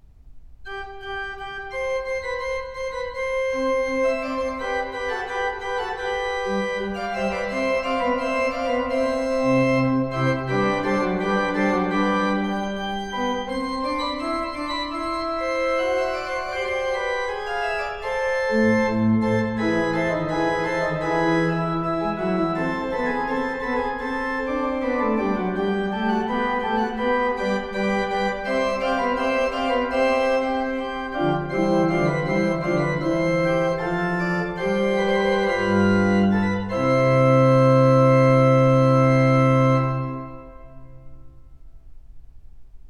Pachelbel_C_major_fugue.ogg